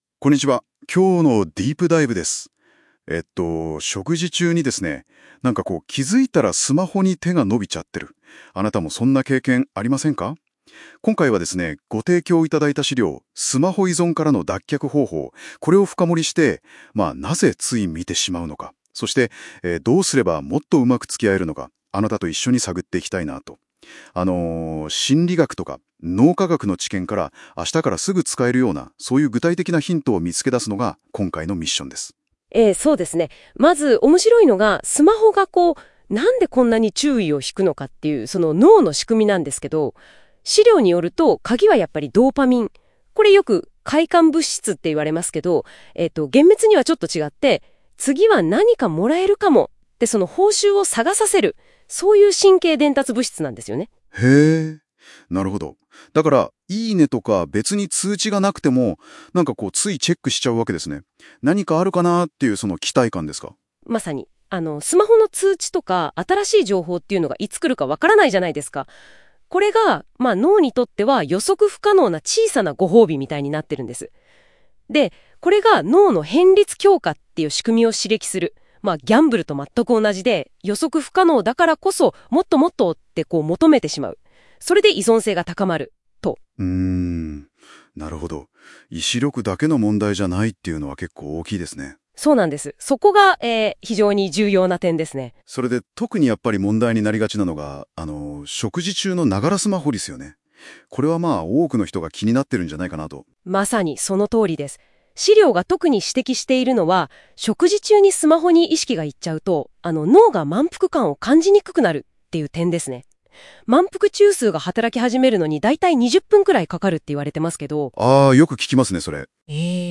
音声解説：スマホ依存を断ち切る！食卓から始めるデジタルデトックス。ドーパミンを操り、豊かな時間を取り戻す心理学×脳科学のヒント